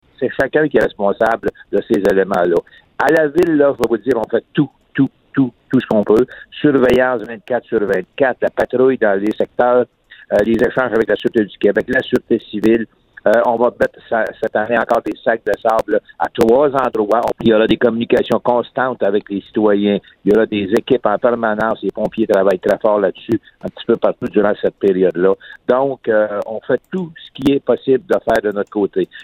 Le maire de Bécancour, Jean-Guy Dubois :